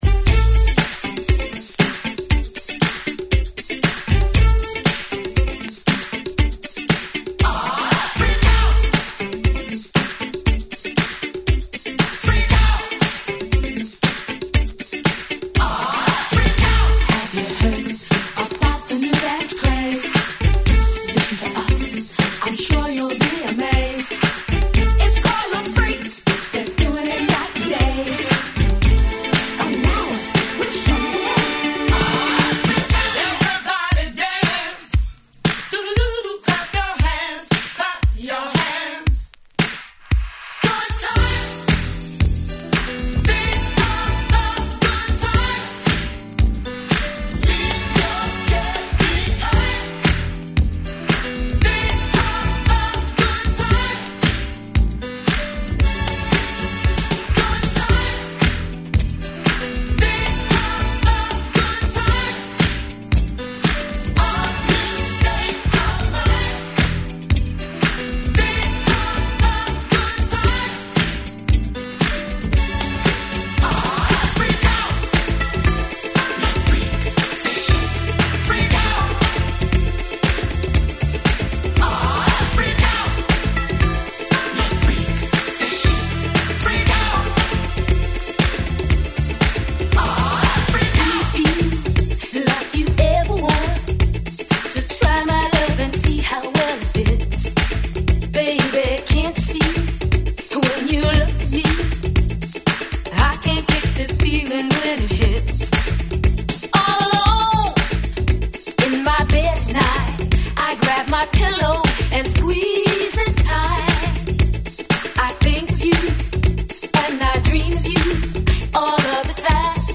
名曲盛り沢山のHit曲Medley!!